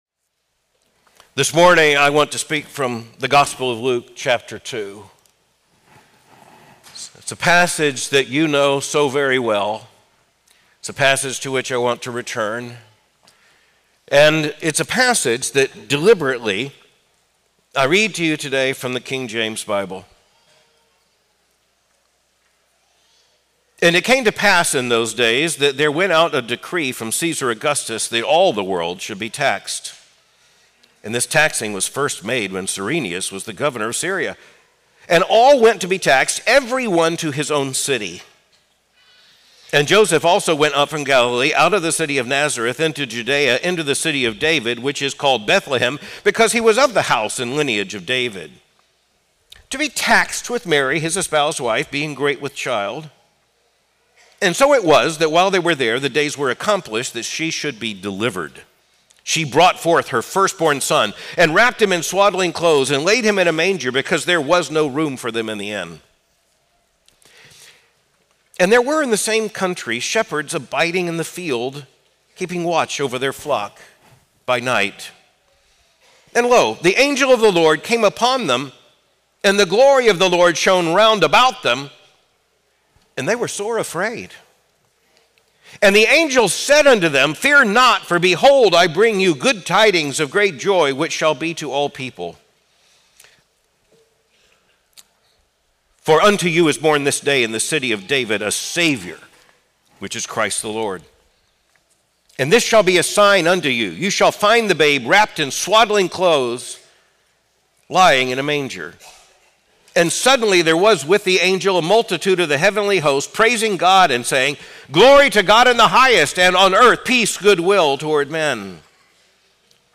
Home · Chapel · And They Went with Haste: The Urgency of Gospel Ministry